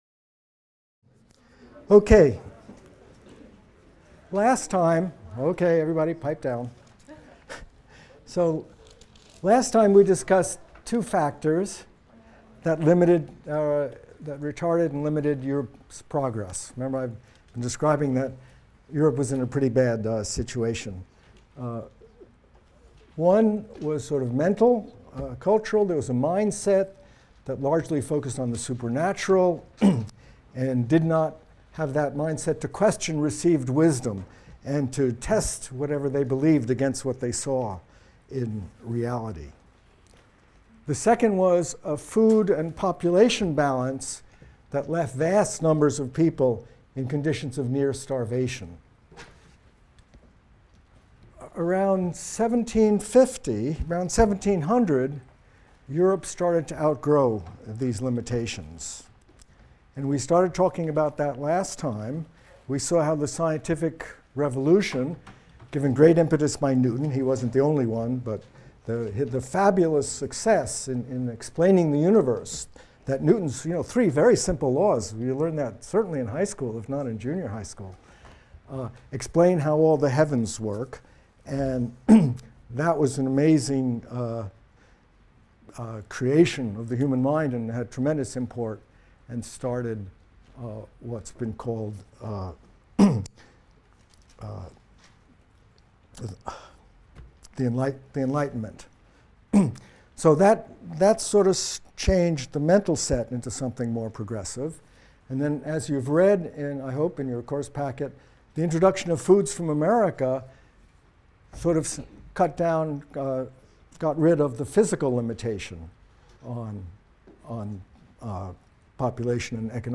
MCDB 150 - Lecture 8 - Demographic Transition in Europe; Fertility Decline | Open Yale Courses